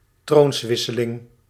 Ääntäminen
IPA : /səkˈsɛʃ.ən/